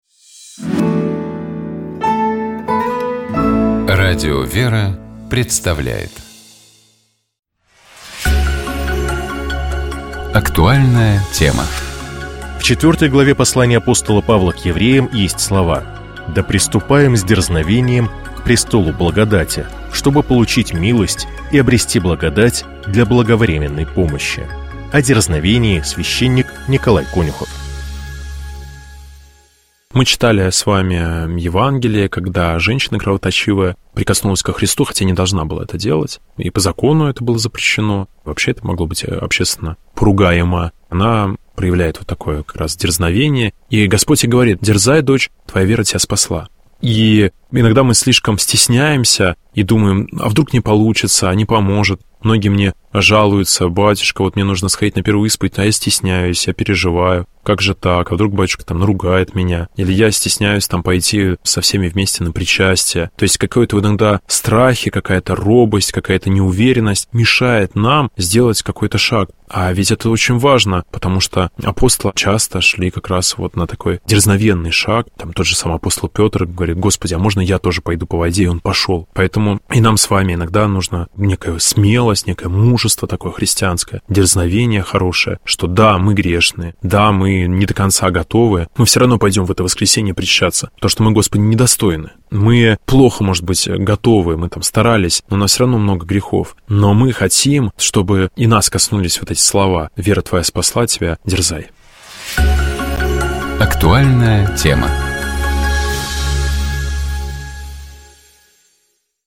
О дерзновении — священник